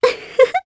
Ophelia-Vox_Happy1_jp.wav